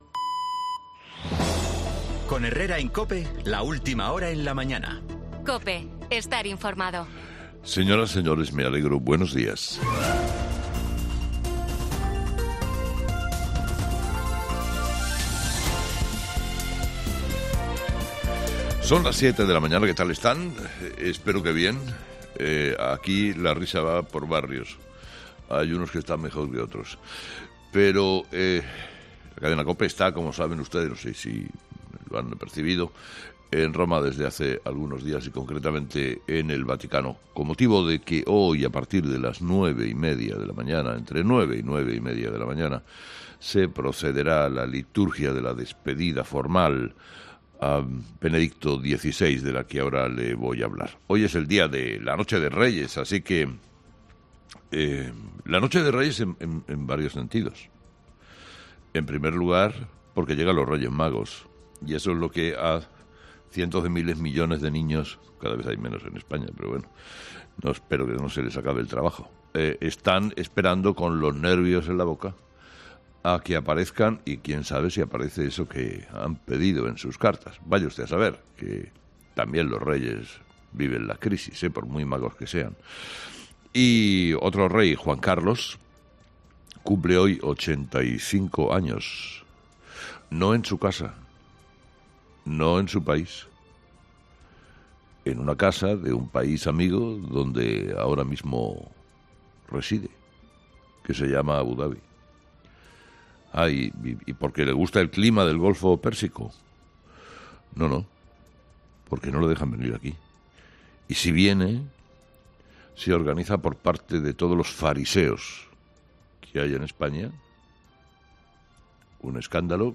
Carlos Herrera en el Vaticano
El programa de este jueves, 5 de enero, comienza desde El Vaticano, donde se ha desplazado un amplio equipo de COPE para contarte el último adiós a Benedicto XVI